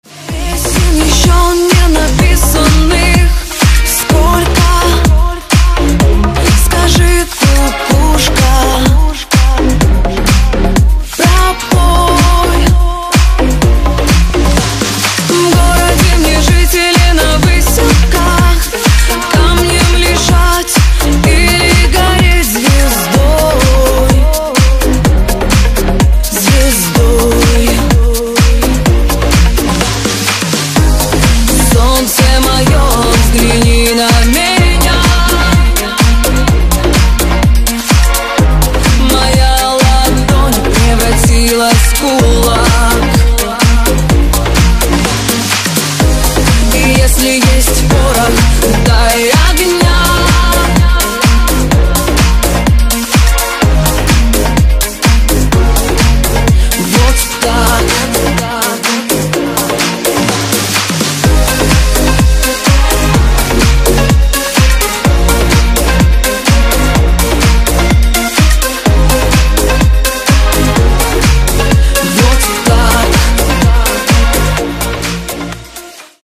• Качество: 192, Stereo
громкие
женский вокал
deep house
dance
EDM
Club House
электронная музыка